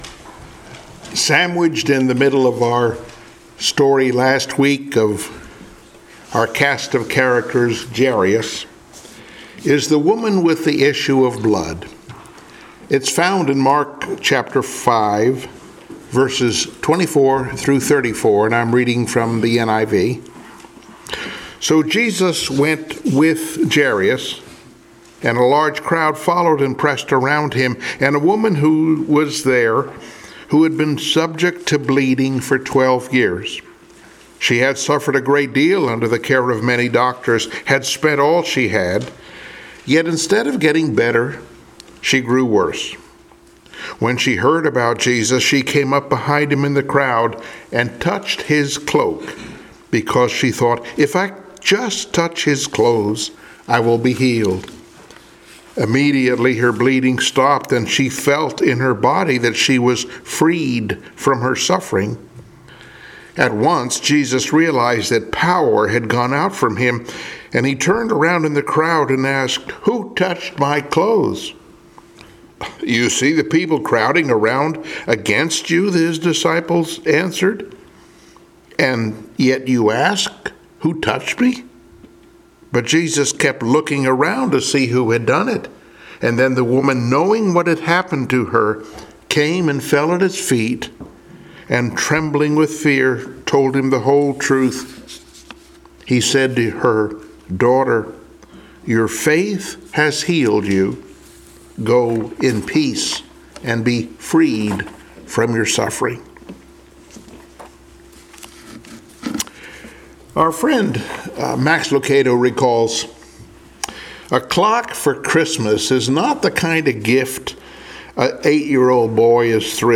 Cast of Characters Passage: Mark 5:24-34 Service Type: Sunday Morning Worship Mark 5:24-34 24 And he went with him.